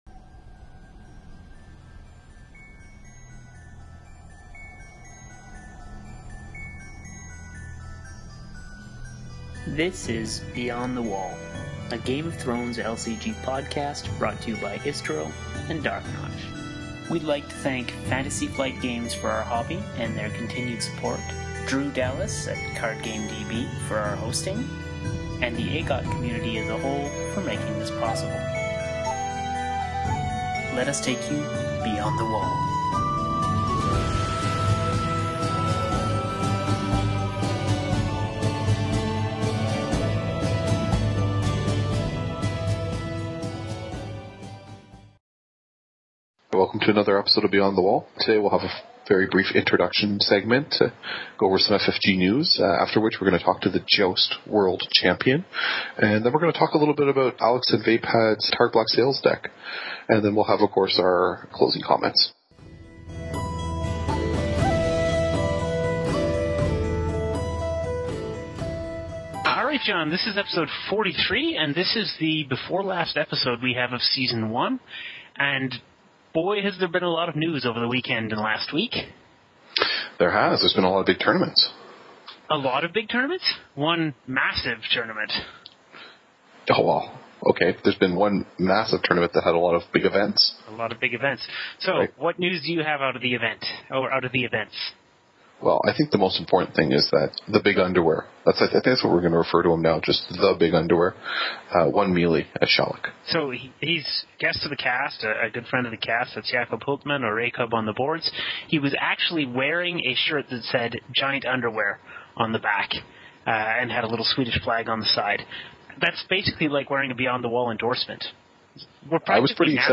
Podcast Description: We start with some FFG & Stahleck news, and then jump right into part 3 of our Worlds 2014 coverage: The Joust. We start off with an interview